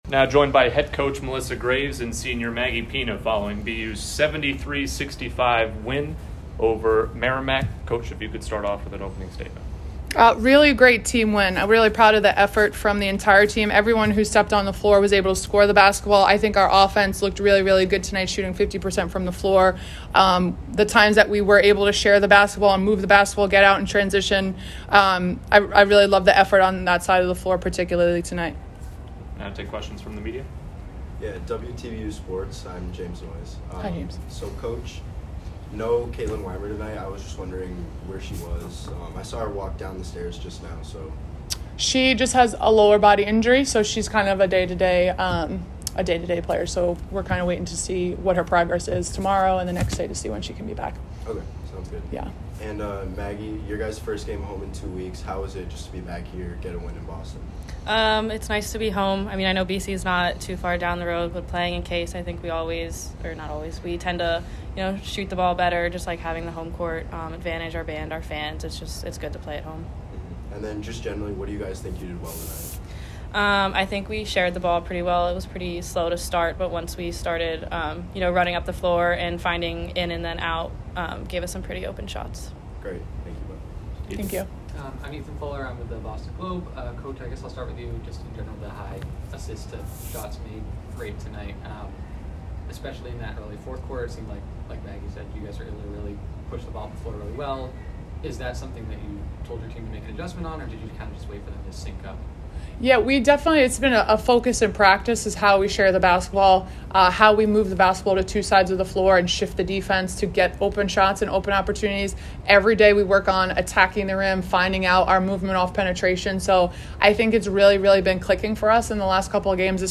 Women's Basketball / Merrimack Postgame Press Conference (12-7-22) - Boston University Athletics